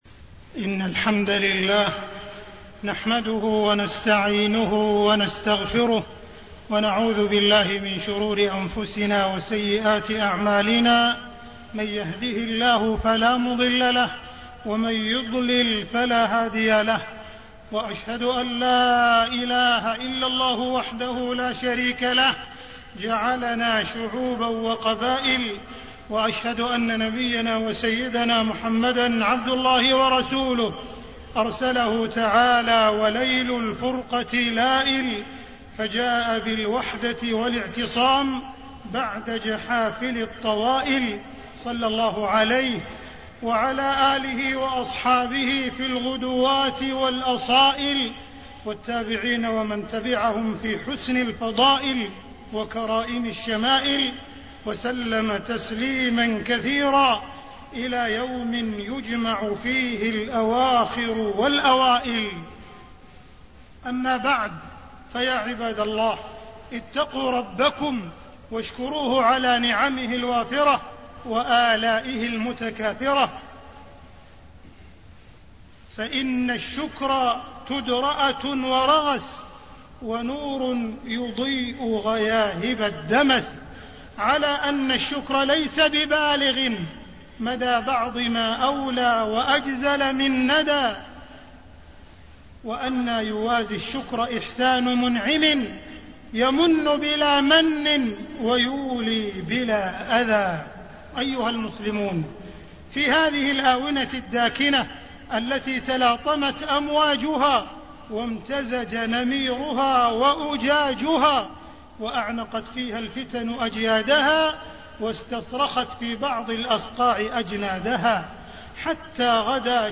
تاريخ النشر ٢٤ جمادى الأولى ١٤٣٤ هـ المكان: المسجد الحرام الشيخ: معالي الشيخ أ.د. عبدالرحمن بن عبدالعزيز السديس معالي الشيخ أ.د. عبدالرحمن بن عبدالعزيز السديس فتنة التفرق والاختلاف